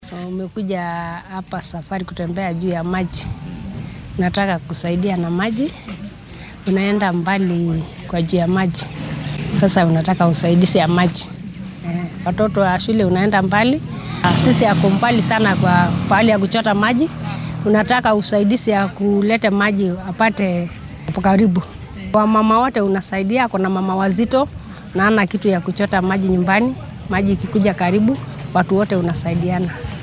Shacabka deggan ismaamulka Kajiado ayaa maanta sameeyay socdaal 6 kiiloomitir ah si ay u uruuriyaan lacaga lagu dhisaayo ceel biyood loogu talagalay dadka ay sameyeen abaaraha. Qaar ka mid ah dadka deegaanka oo warbaahinta la hadlay ayaa sidatan yiri.